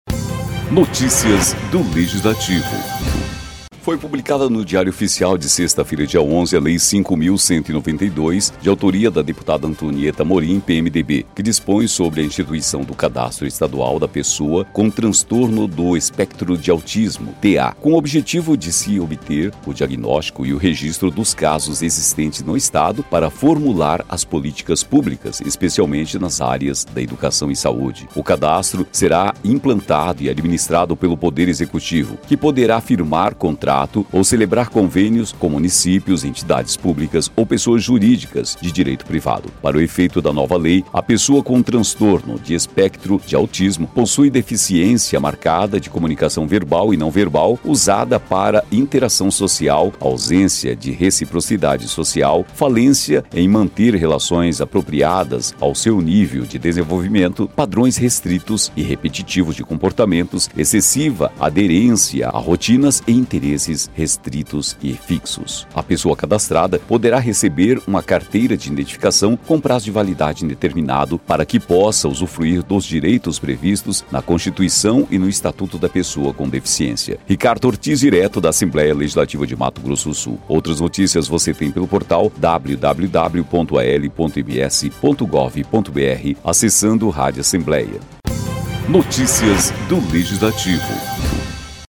Equipe Rádio Assembleia em 11/05/2018 15:54:00